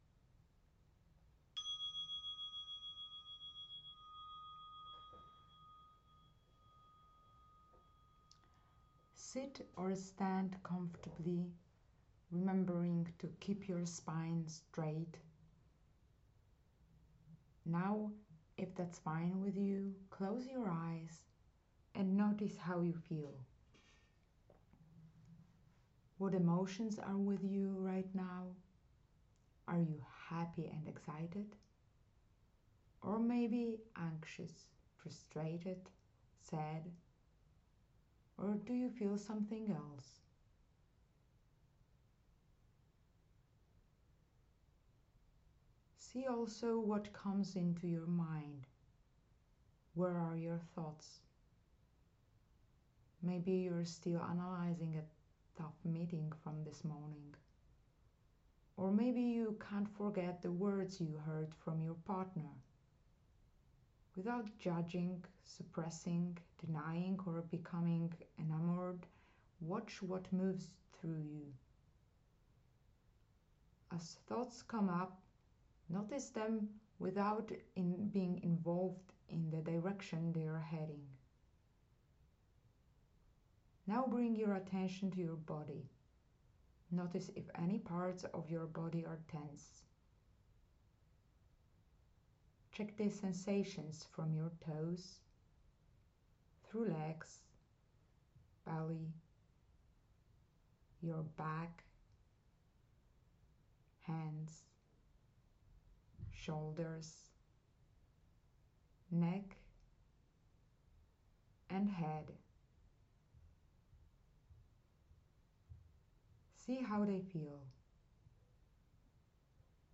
To make these transitions easier for you, I have prepared a short mindful breathing meditation (6 min). It consists of 3 parts: noticing thoughts, emotions and sensations from the body, mindful breathing and returning your attention to the surroundings and your own body.